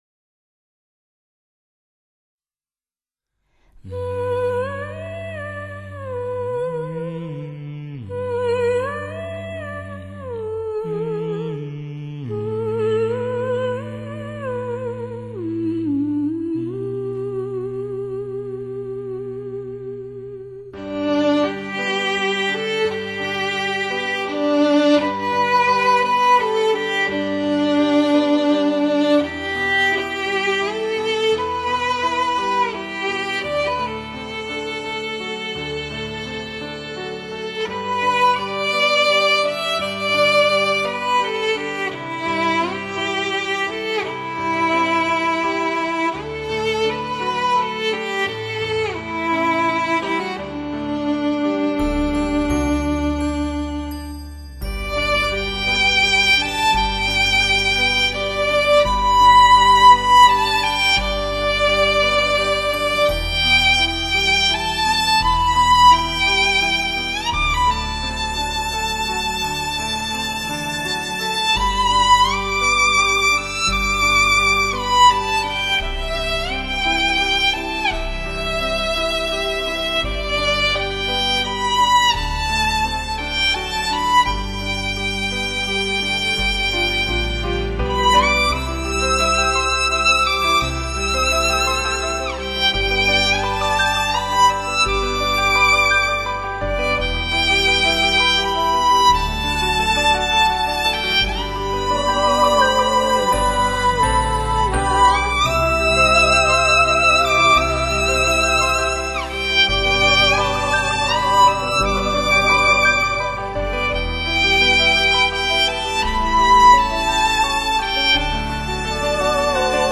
小提琴演奏
浓浓松香味精彩连弓控制瑰丽琴音不二之选。